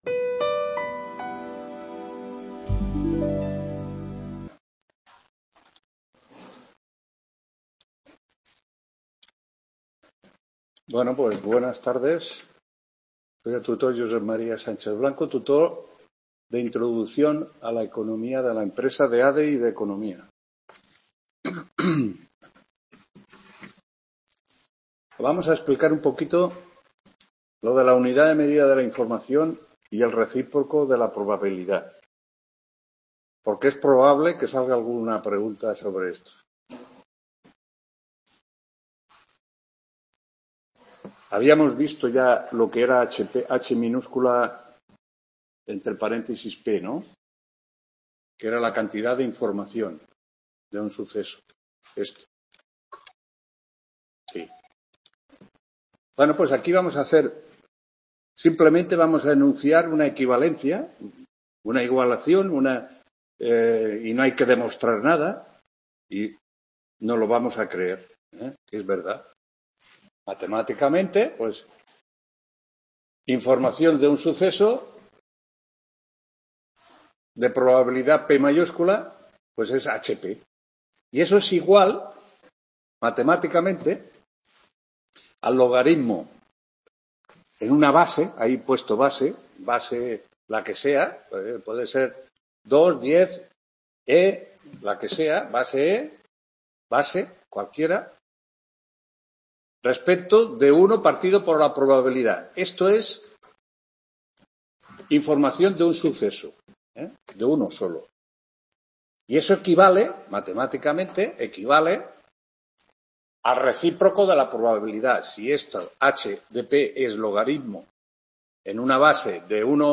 6ª TUTORÍA INTRODUCCIÓN A LA ECONOMÍA DE LA EMPRESA 22…